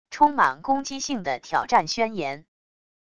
充满攻击性的挑战宣言wav音频